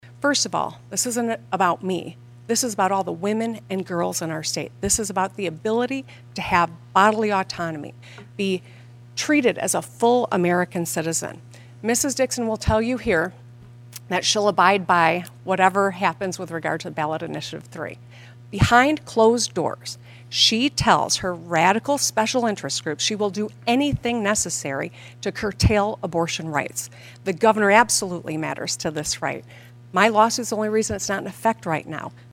The debate brought the two contenders together for hour-long event at Oakland University.